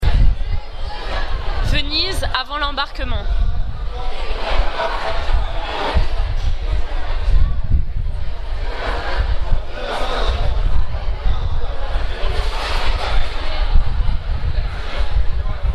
Sur le parking de Tronchetto, avant l'embarquement du vaporetto vers Venise.